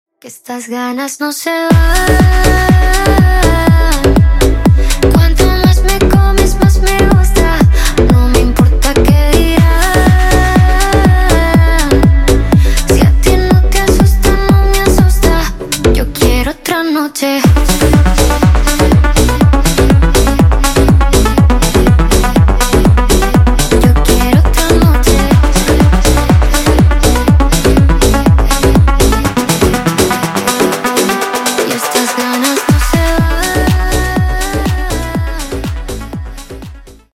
Клубные Рингтоны » # Латинские Рингтоны
Поп Рингтоны